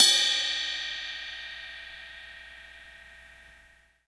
Cymbol Shard 16.wav